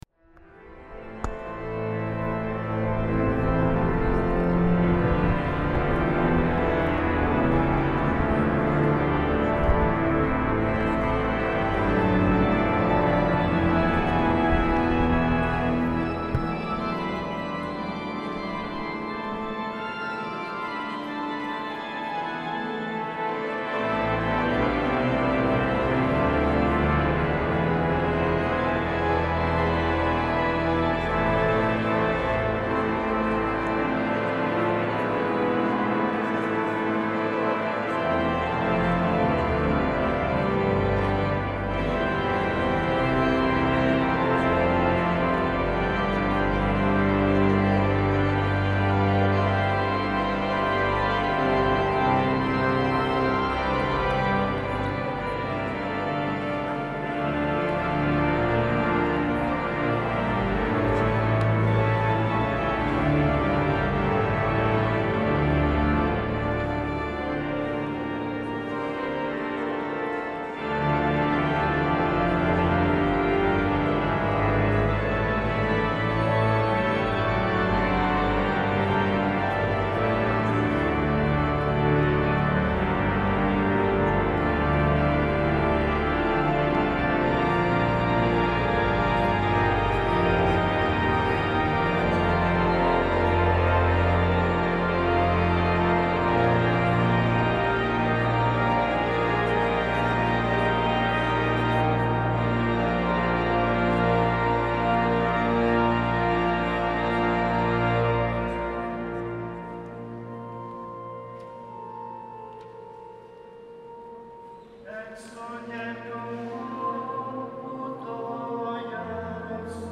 Loiolako beila 2022-07-14 Bezperak
Célébrations